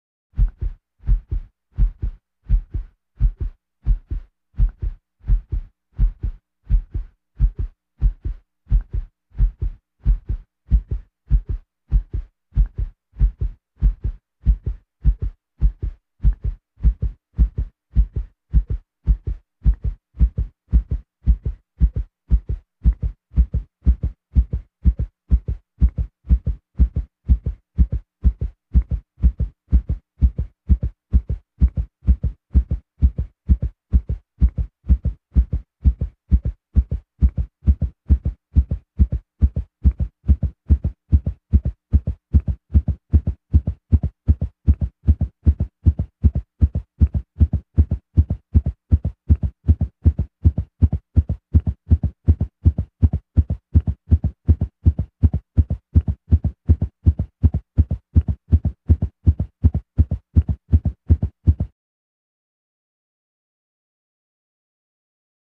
Heartbeat, Speeds Up